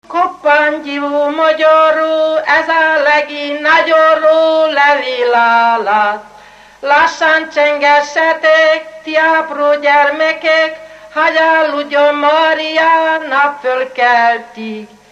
Felföld - Nyitra vm. - Zsére
ének
Stílus: 7. Régies kisambitusú dallamok